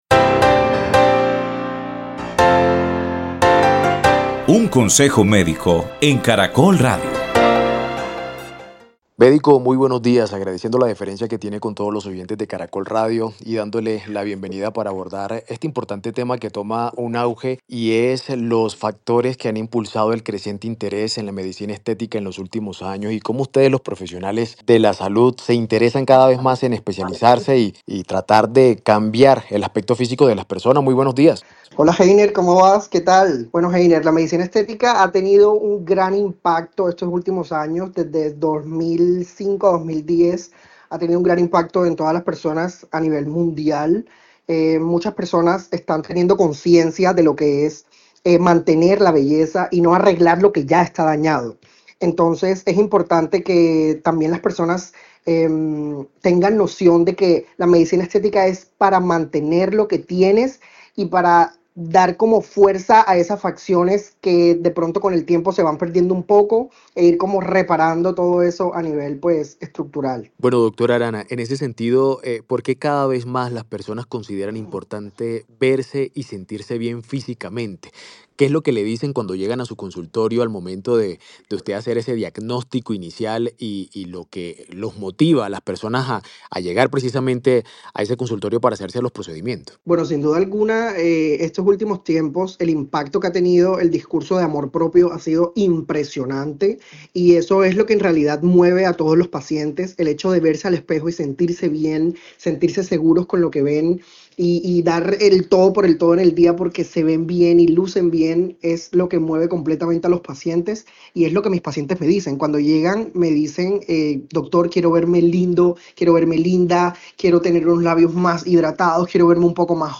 En diálogo con Caracol Radio, este profesional de la salud señala los distintos factores que han impulsado la creciente demanda de procedimientos estéticos en los últimos años.